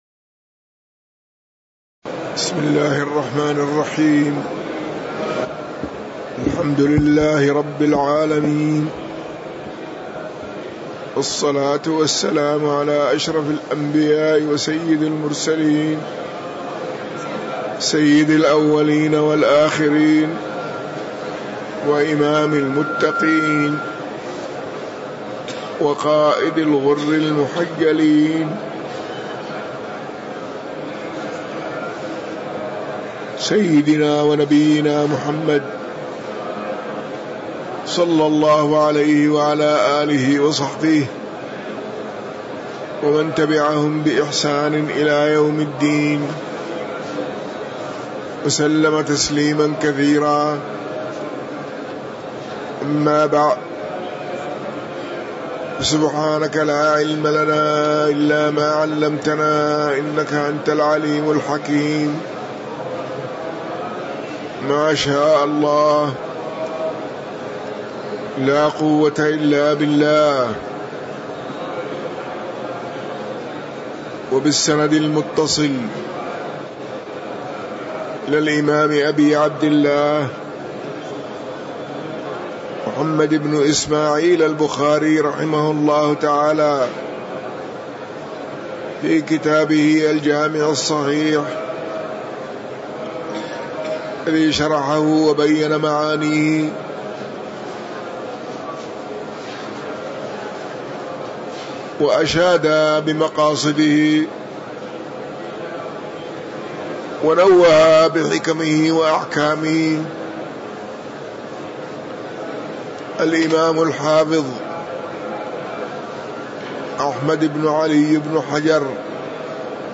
تاريخ النشر ١٢ رمضان ١٤٤٣ هـ المكان: المسجد النبوي الشيخ